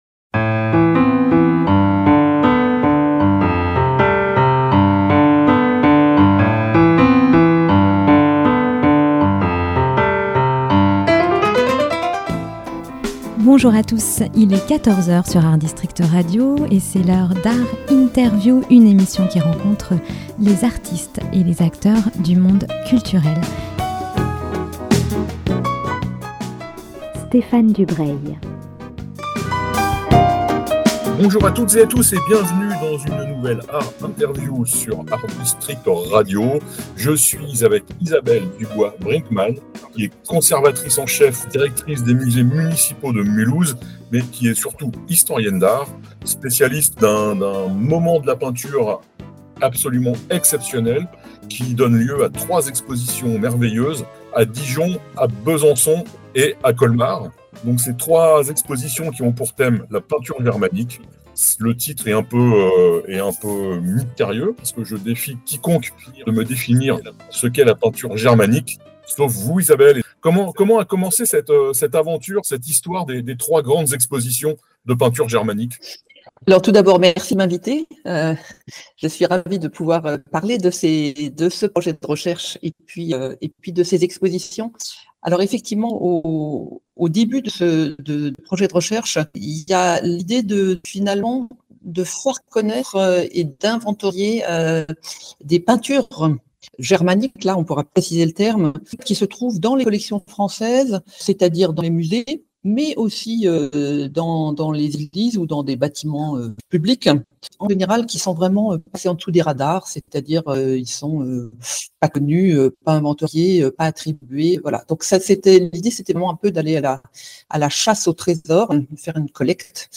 ART INTERVIEW lundi et jeudi à 14h.